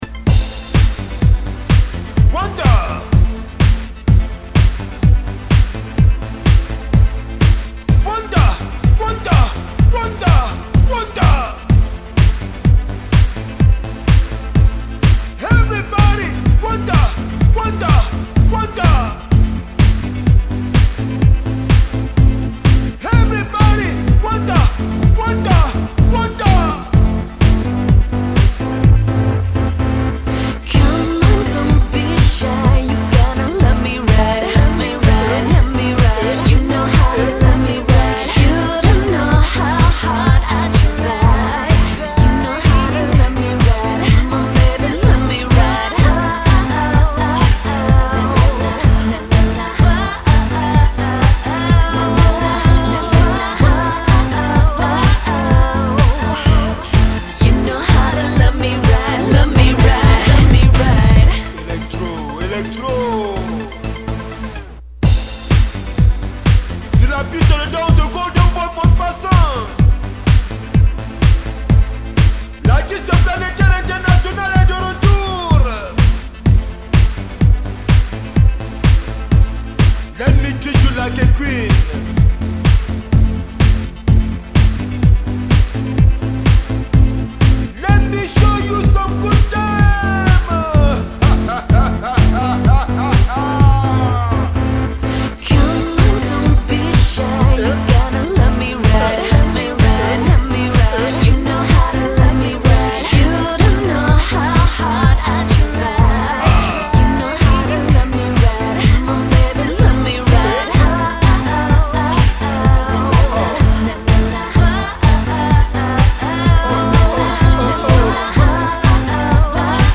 Electro